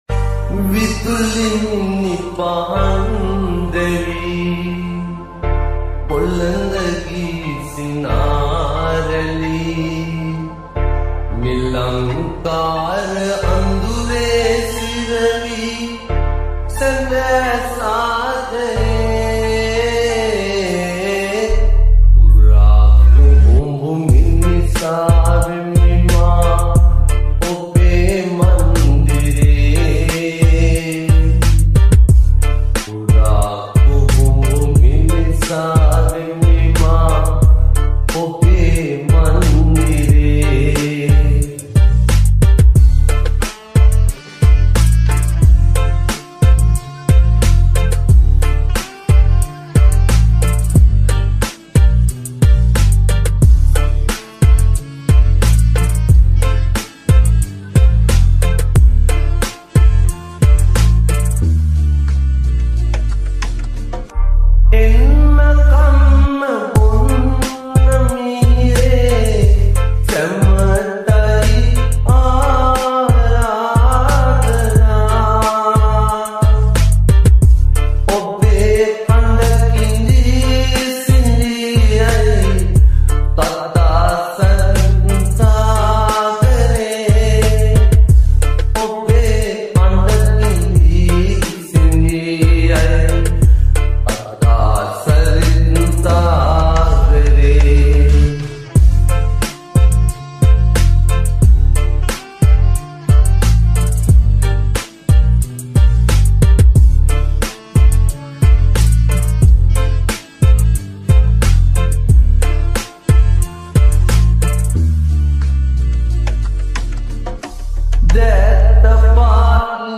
EDM Remix New Song